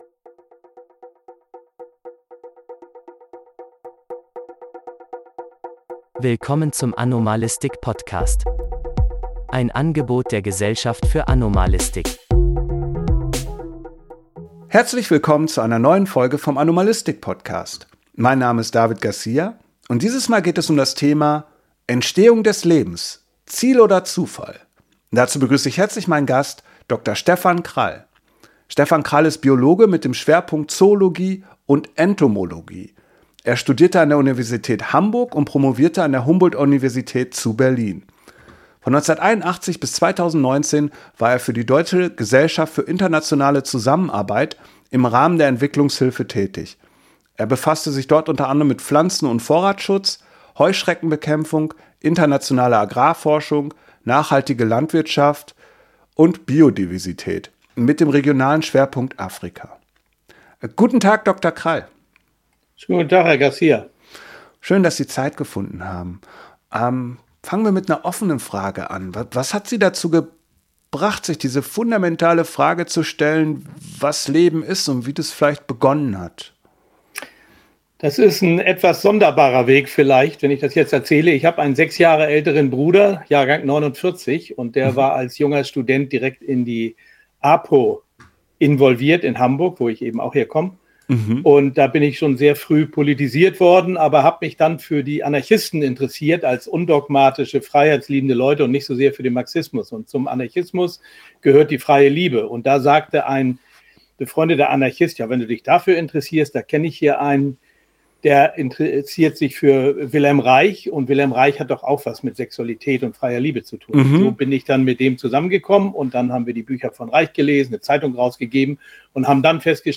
Biologe mit dem Schwerpunkt Zoologie und Entomologie